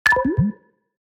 Simulation, Building, Game Menu, Ui Confirm Sound Effect Download | Gfx Sounds
Simulation-building-game-menu-ui-confirm.mp3